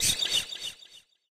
vampire_bat_angry.ogg